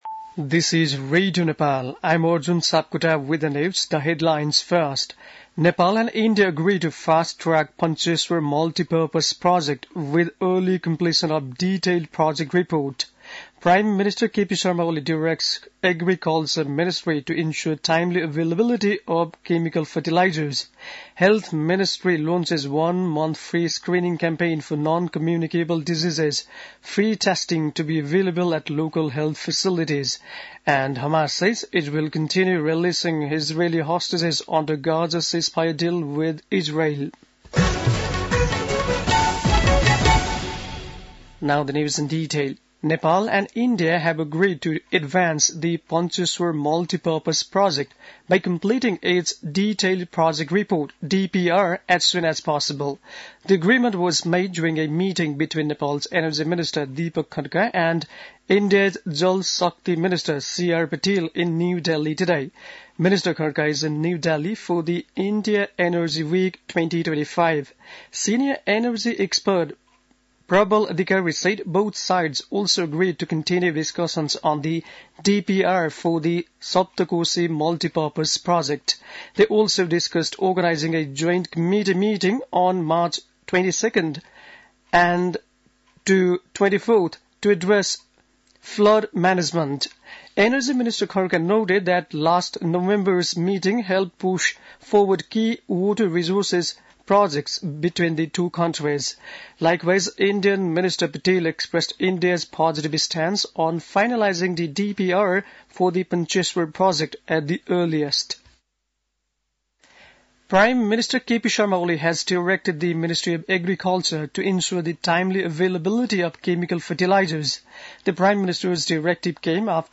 बेलुकी ८ बजेको अङ्ग्रेजी समाचार : २ फागुन , २०८१
8-pm-english-news-11-01.mp3